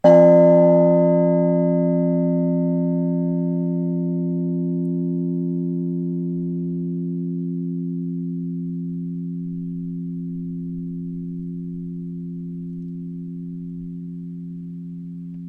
Church Bells
65" Meneely complete swinging dated 1921